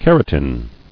[car·o·tin]